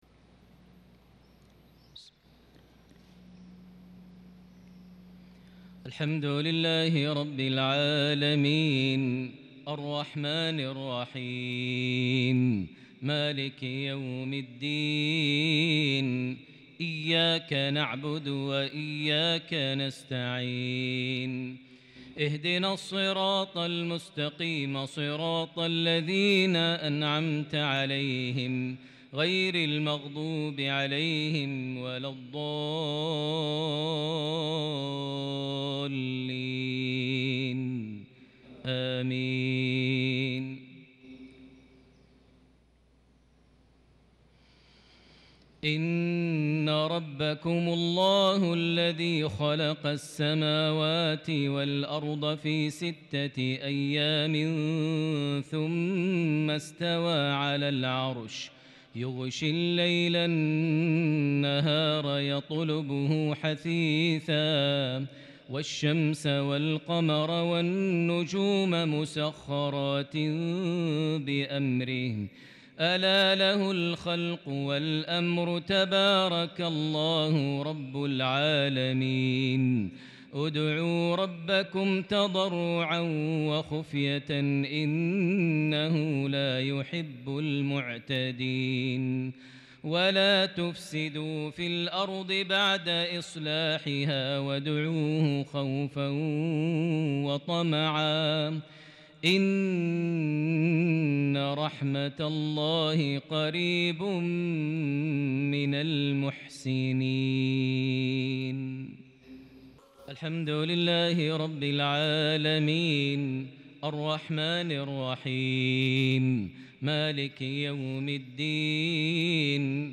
mghrip 7-9- 2021 prayer from Surah Al-Araf 54- 58 > 1443 H > Prayers - Maher Almuaiqly Recitations